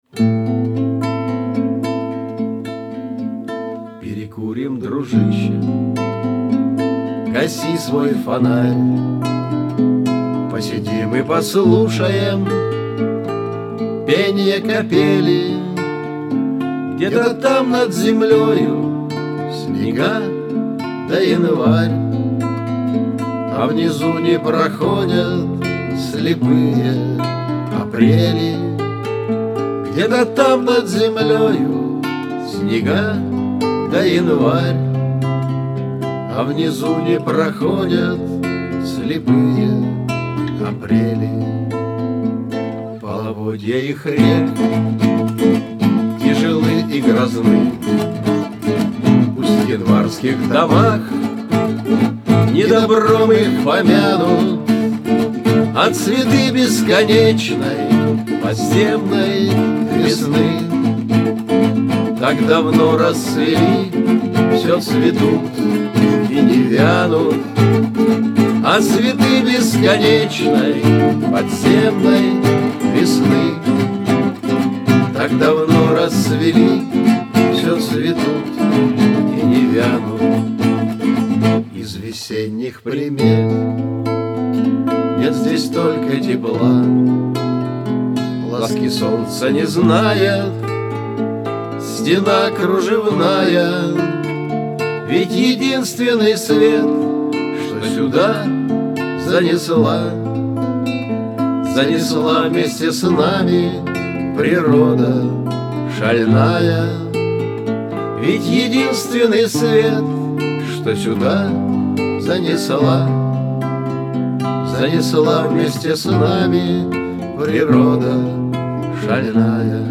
Пение капели